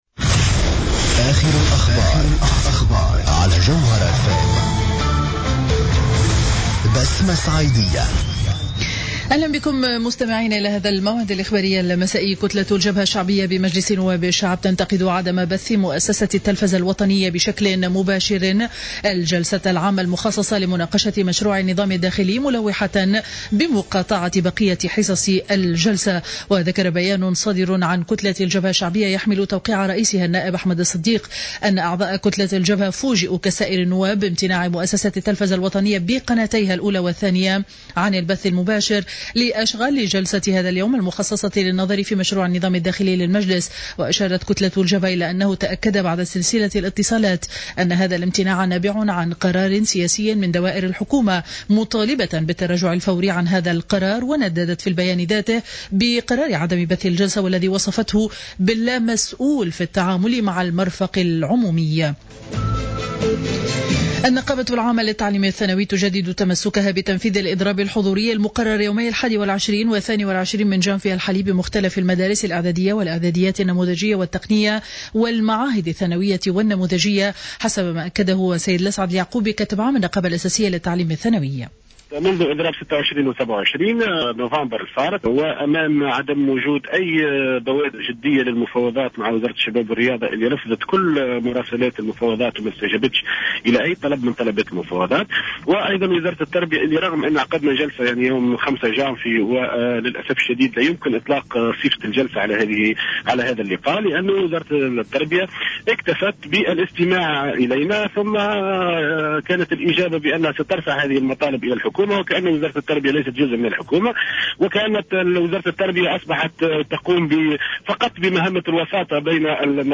نشرة أخبار السابعة مساء ليوم الثلاثاء 20-01-15